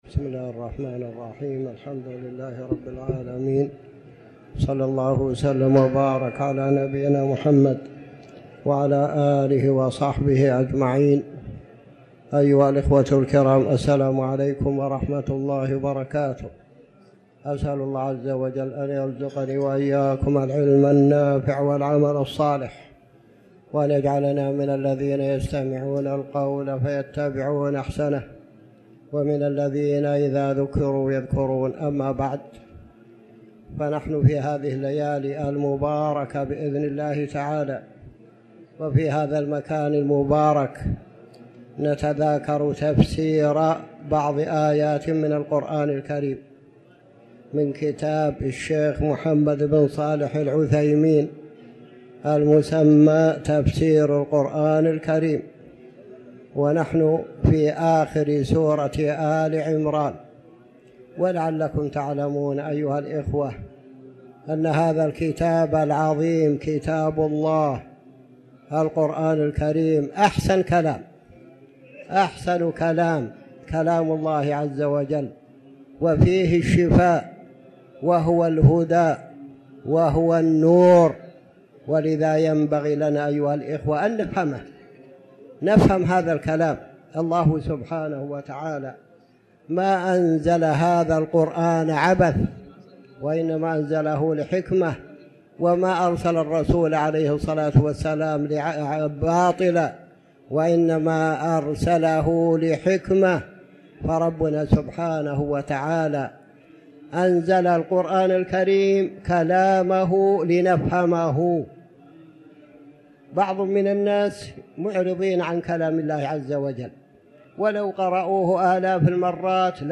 تاريخ النشر ٢ ربيع الأول ١٤٤٠ هـ المكان: المسجد الحرام الشيخ